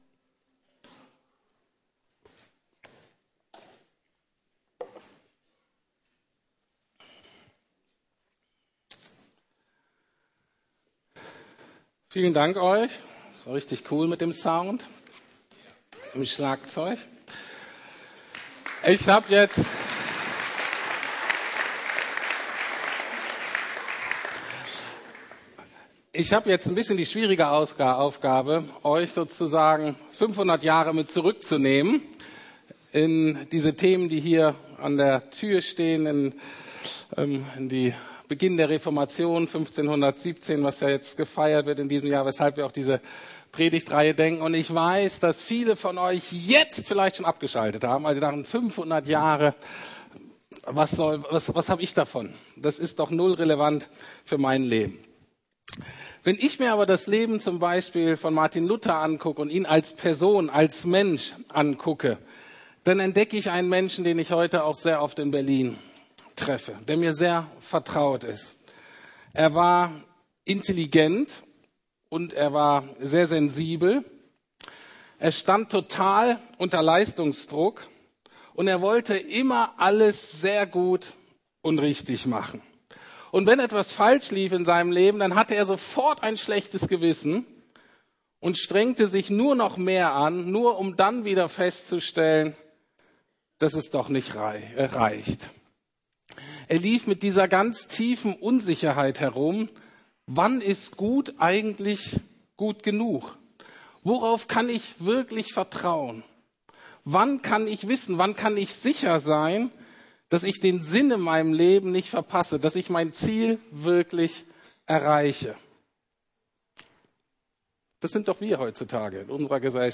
Sola Scriptura - Allein die Schrift ~ Predigten der LUKAS GEMEINDE Podcast